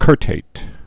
(kûrtāt)